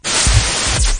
WhiteStatic_OnOff_OneSec.wav